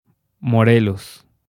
Summary Description Morelos3.ogg Español: Pronunciación del nombre del Estado de Morelos en México.